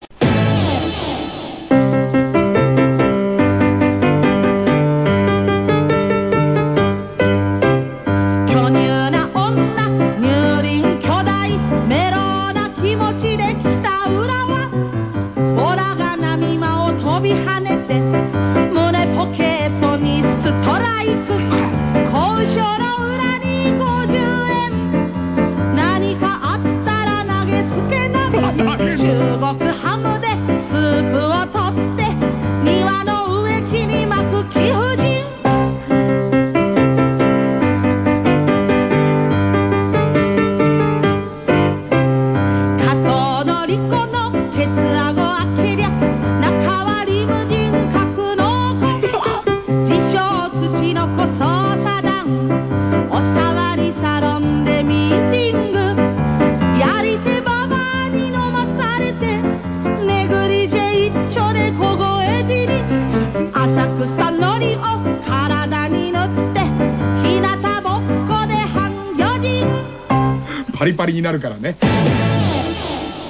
昔あった電波歌コーナーで採用された替え歌。